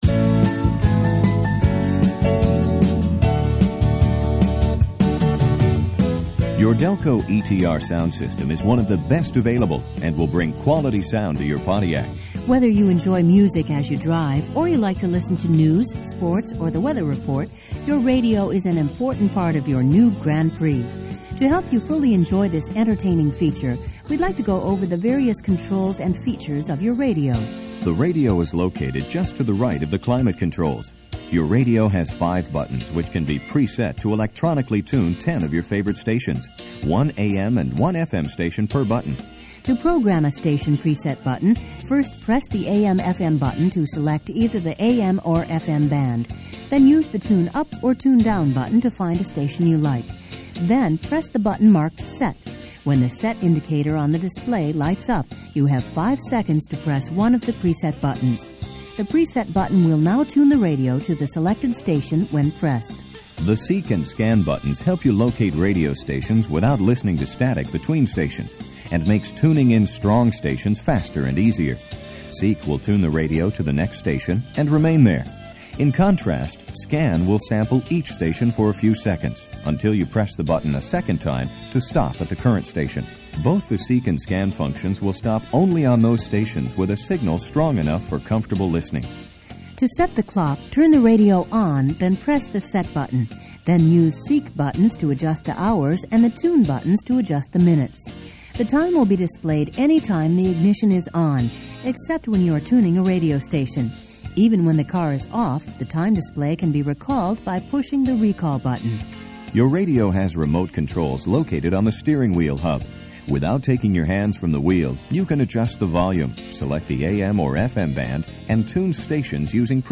the tape itself needs cleaned, a little muffling on the audio, thats it!
Dig the corny synth music on side 2.